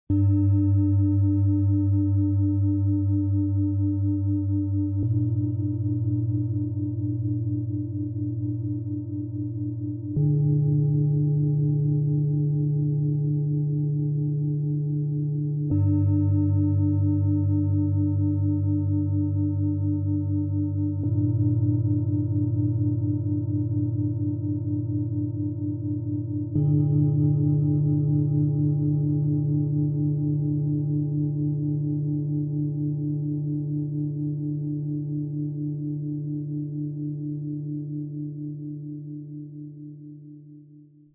Verwurzelt und klar: Erdung spüren, innere Ordnung finden, mit gutem Bauchgefühl wachsen - Set aus 3 Planetenschalen, Ø 20,2 -27,2 cm, 3,87 kg
Eine tiefe, strukturierende Schwingung begleitet den Weg zur inneren Mitte.
Ein sanfter Klang, der Geborgenheit und innere Klarheit unterstützt.
Im Sound-Player - Jetzt reinhören kann der Original-Ton dieser drei handgefertigten Schalen angehört werden. Ihre Schwingungen entfalten eine tiefe, erdende und zentrierende Wirkung.
Tiefster Ton: Tageston, Saturn
Bengalen-Schale, matt, Durchmesser 27,2 cm, Höhe 11 cm